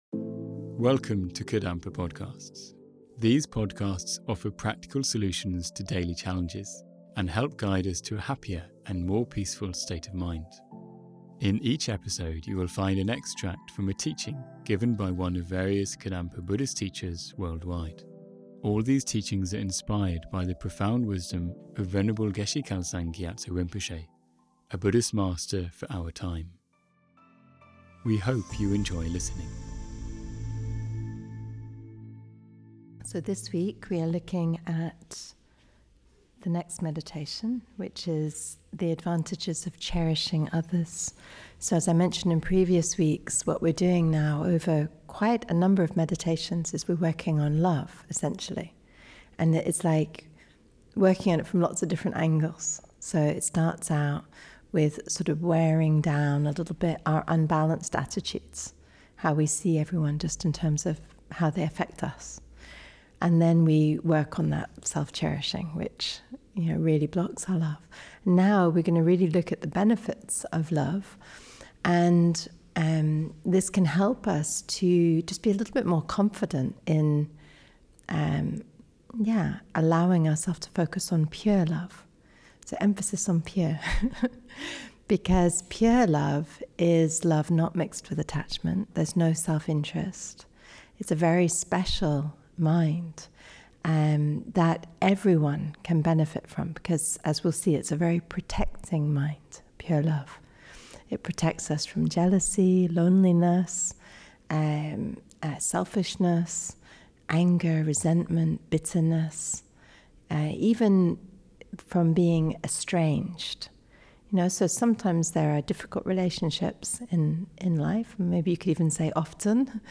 Around Christmas time these minds can arise quite strongly so we need the mind of love to protect us from them. As a Christmas present this episode includes a short guided meditaion.